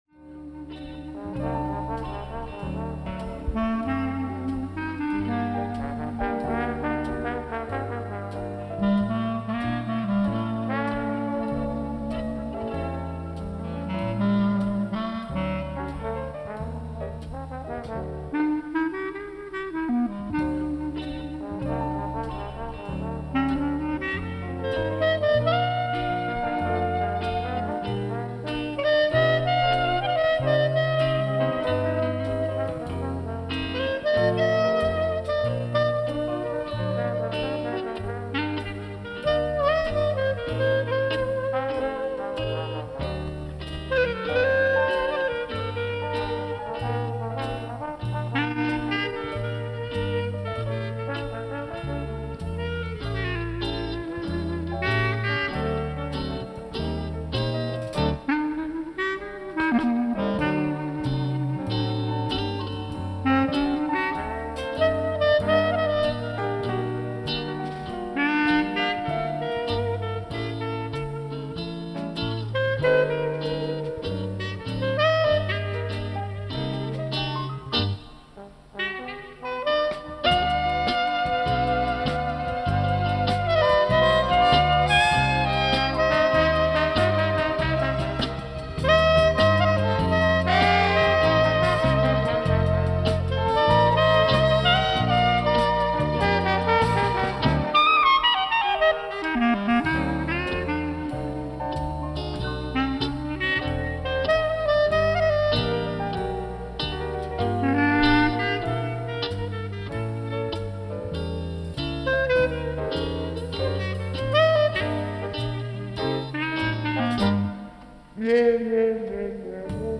VARIÉTÉS INTERNATIONALES ET ANIMATIONS TOUS GENRES